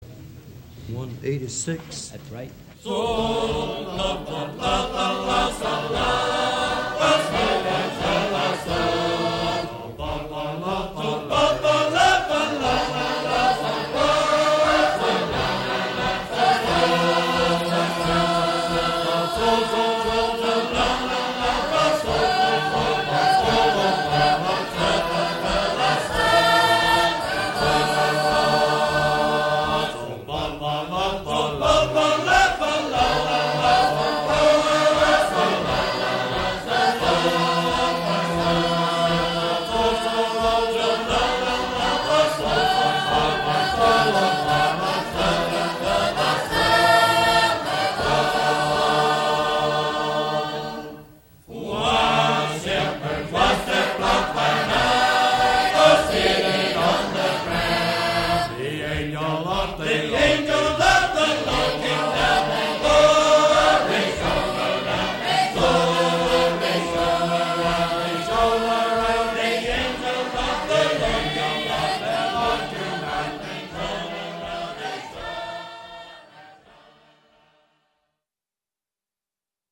テナーに主旋律を置く
ここでの歌唱はアラバマ州に残っていた集会の様子を録音したもので
四重唱のアレンジも男声に重きを置いたもので、ソプラノを主旋律に置くのは
歌唱の前座にシェイプ・ノートをドレミ読みで歌っているのが興味深い。
Sacred_Harp.MP3